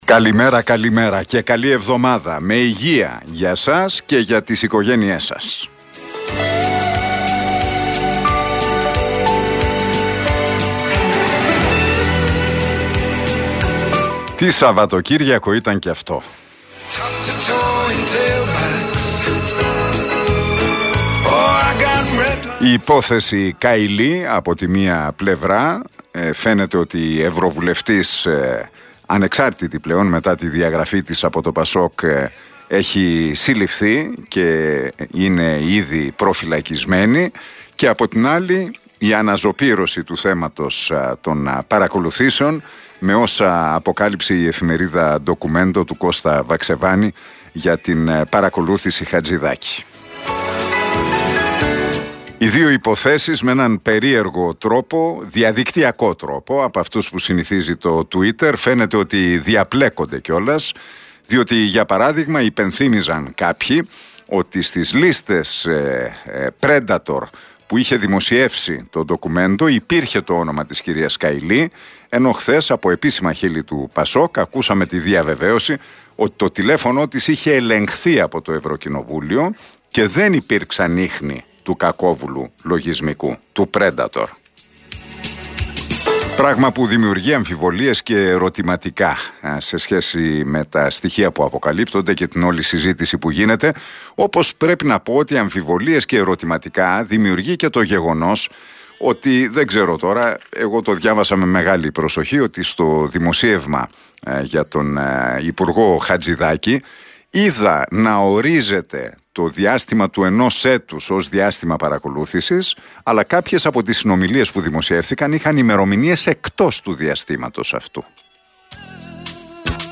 Ακούστε το σχόλιο του Νίκου Χατζηνικολάου στον RealFm 97,8, την Δευτέρα 12 Δεκεμβρίου 2022